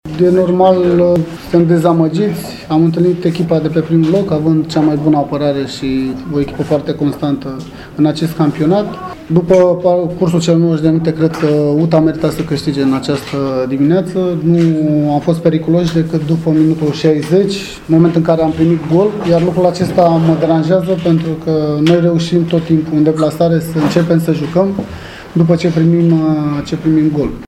Tehnicianul dobrogenilor, Ianis Zicu, era supărat pe atitudinea băieților săi: